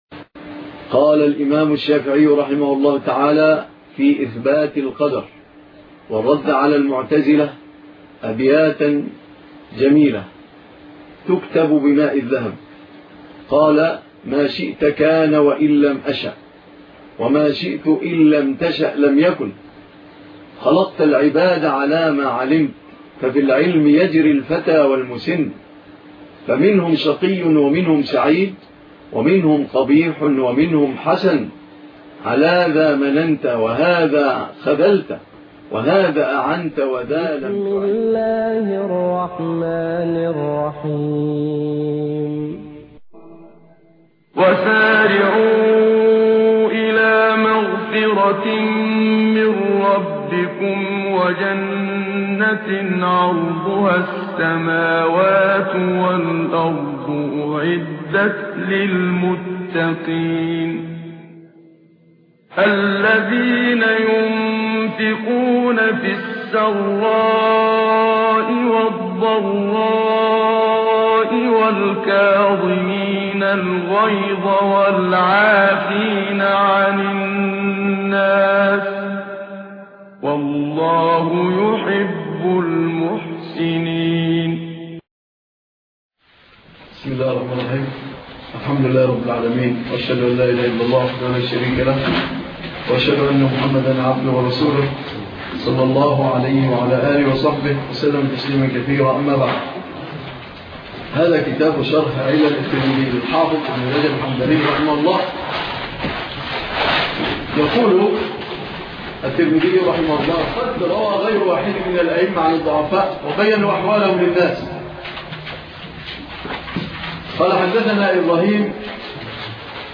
الدرس 9 ( شرح علل ابن رجب